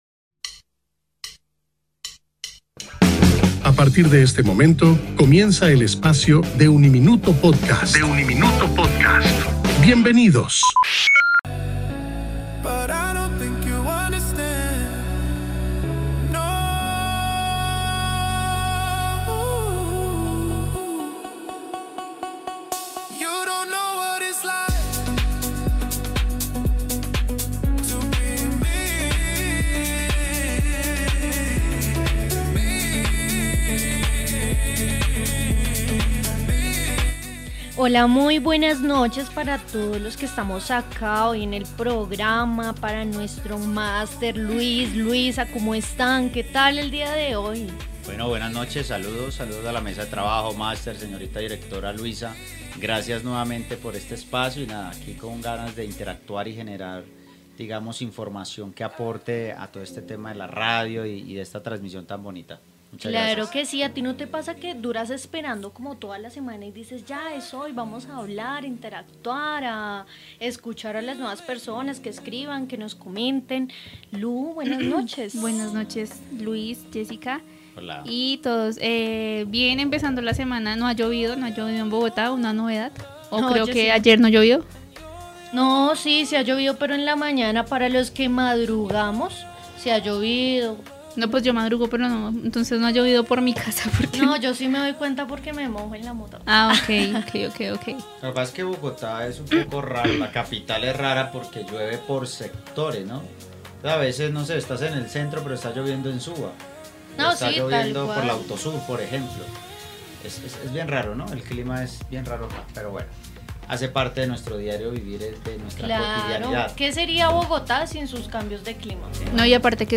No te pierdas este interesante debate sobre los sueños, sus misterios y significados.